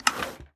Minecraft Version Minecraft Version latest Latest Release | Latest Snapshot latest / assets / minecraft / sounds / ui / loom / select_pattern2.ogg Compare With Compare With Latest Release | Latest Snapshot